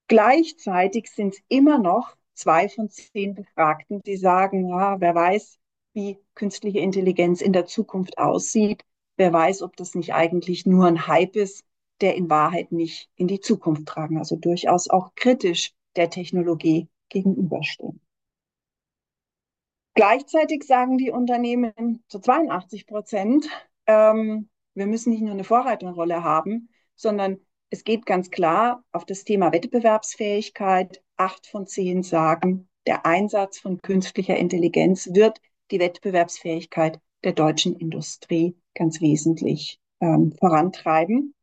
Mitschnitte der Pressekonferenz